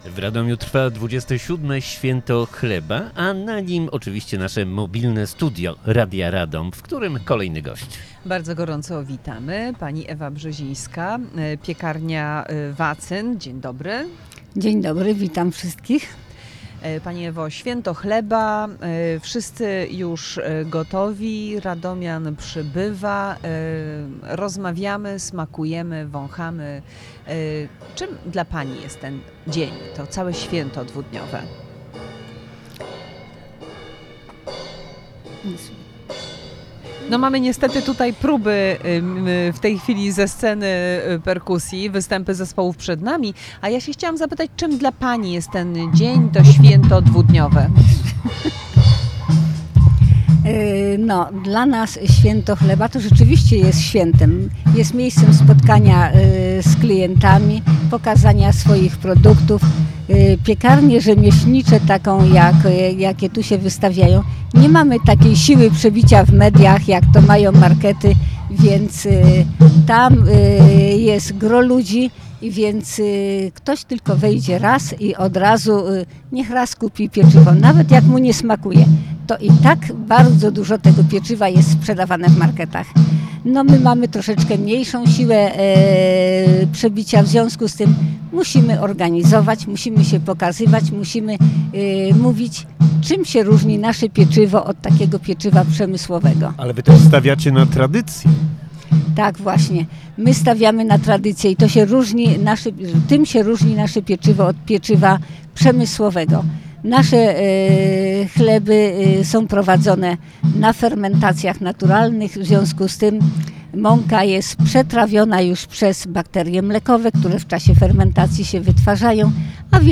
Mobilne Studio Radia Radom na Święcie Chleba 2025 w Radomiu.